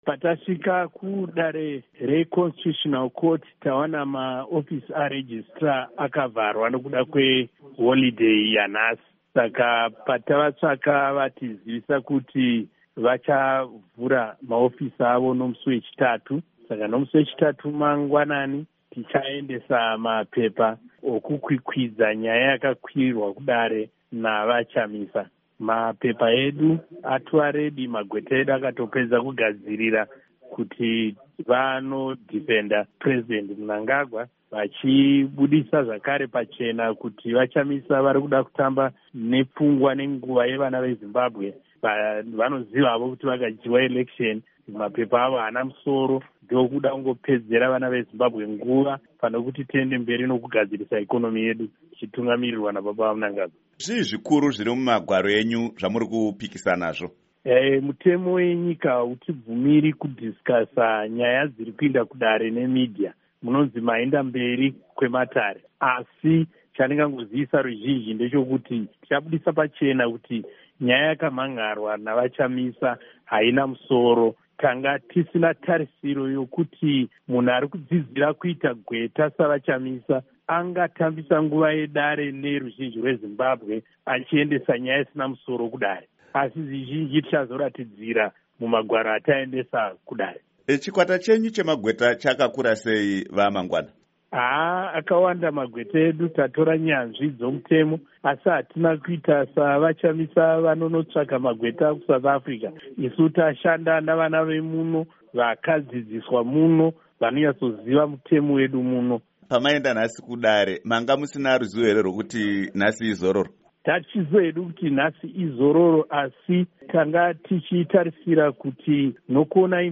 Hurukuro naVaMunyaradzi Paul Mangwana